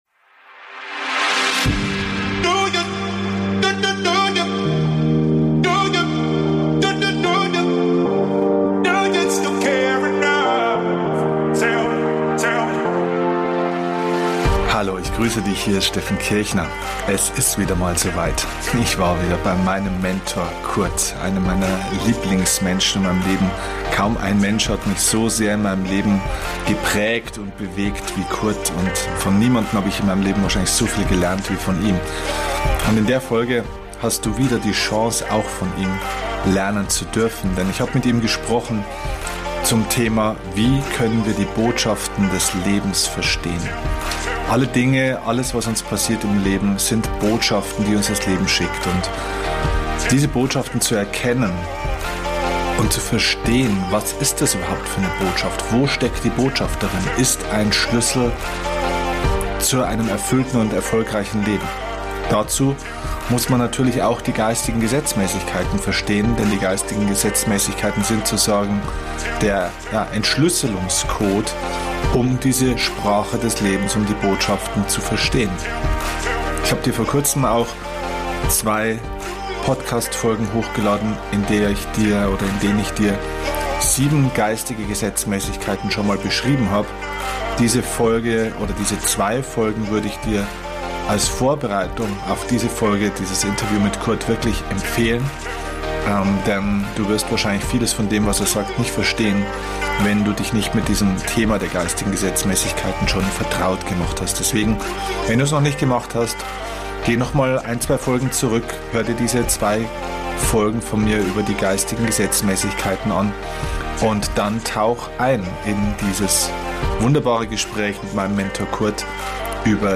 In diesem Gespräch erklärt er, wie wir die Botschaft des Lebens verstehen können. Was es bedeutet, wenn uns verschiedene Dinge im Leben passieren und wie wir daraus wachsen können und neue Hoffnung schöpfen.